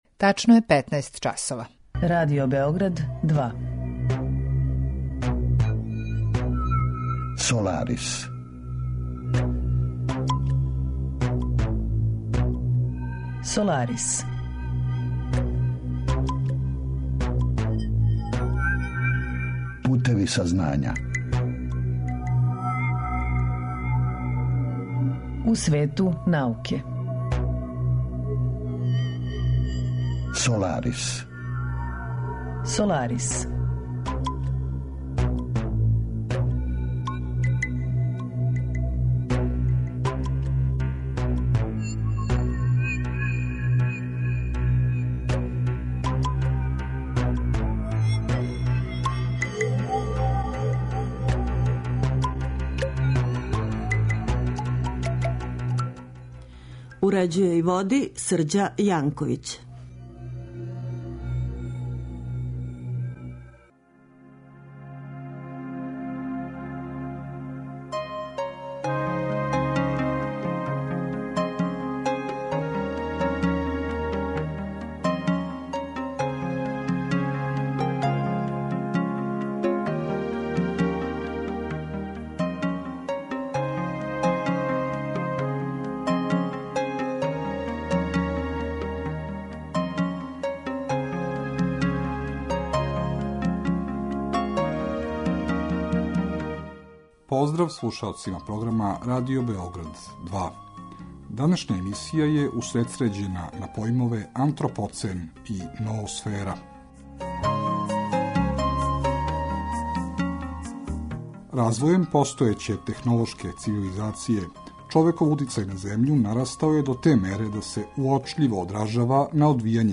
Разговор је први пут емитован 1. априла 2015. године.